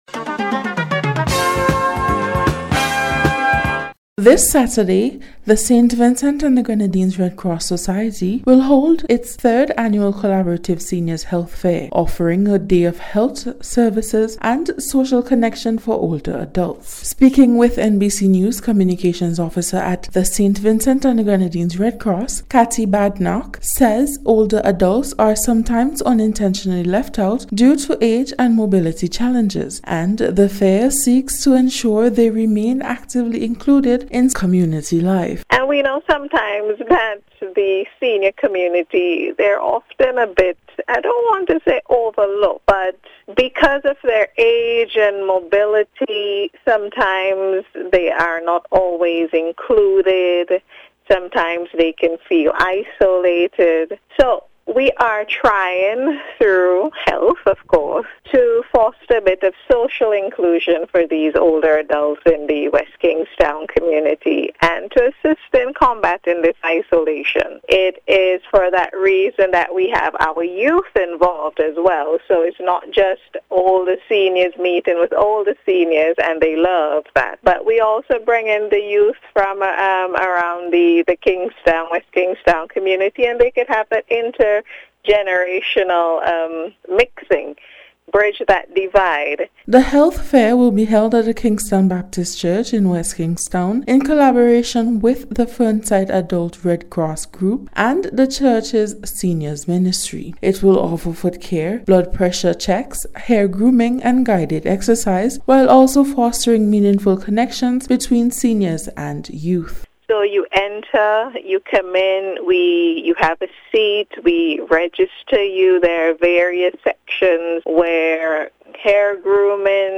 NBC’s Special Report- Tuesday 3rd March,2026